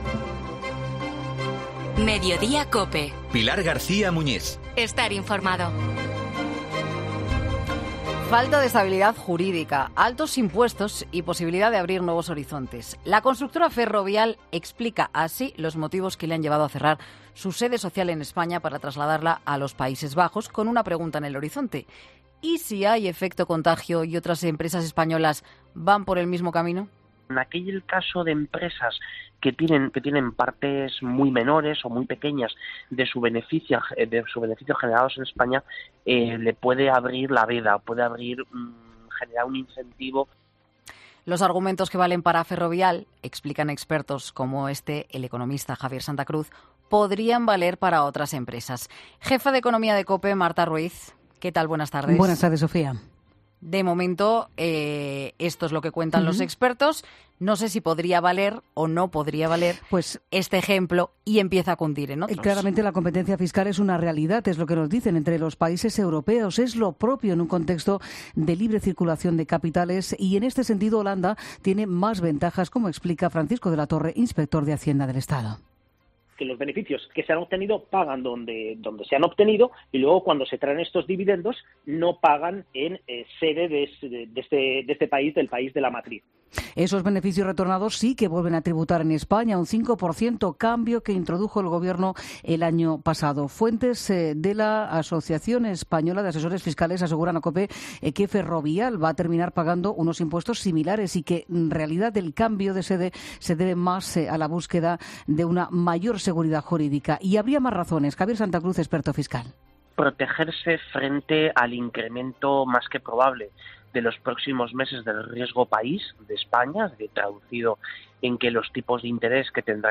Expertos analizan en COPE a qué responde la decisión de la constructora de trasladar su sede social a los Países Bajos